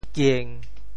娟 部首拼音 部首 女 总笔划 10 部外笔划 7 普通话 juān 潮州发音 潮州 giêng1 文 中文解释 娟 <形> (形声。